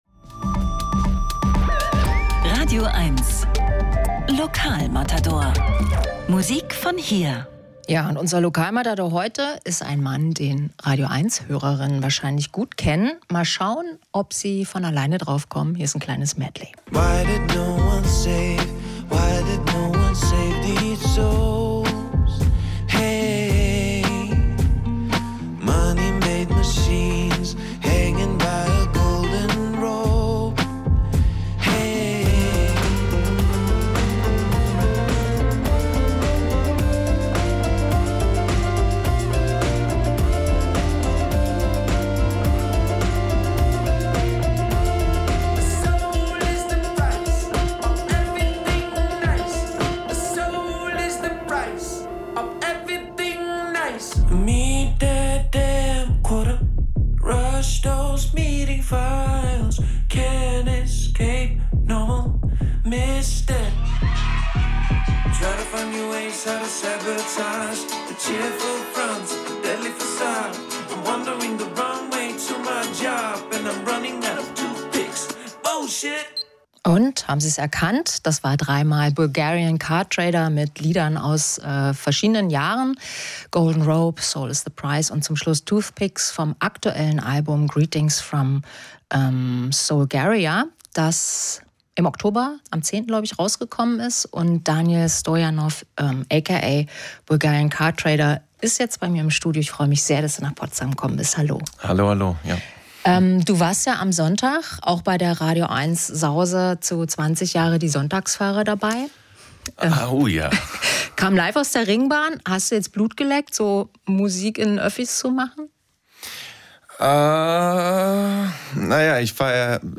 Musik-Interviews
radioeins hat sie alle! Die besten Musikerinnen und Musiker im Studio oder am Telefon gibt es hier als Podcast zum Nachhören.